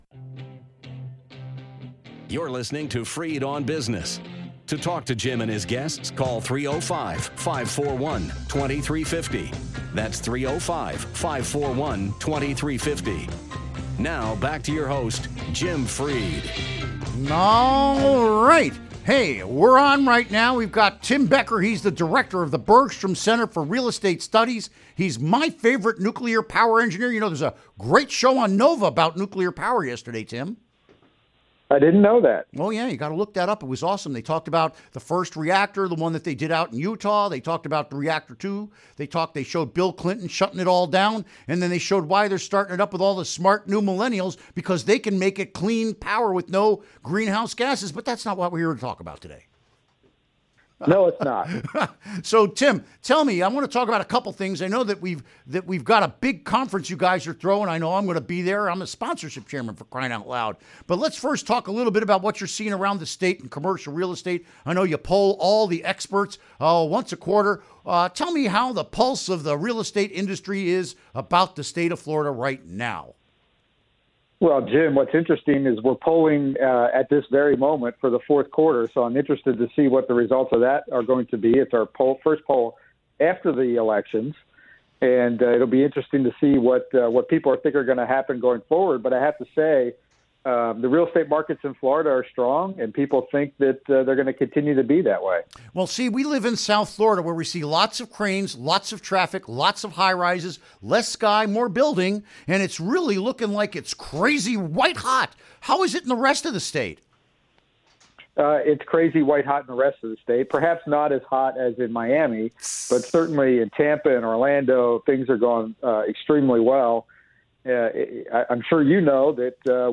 Interview Segment Episode 402: 01-12-17 Download Now!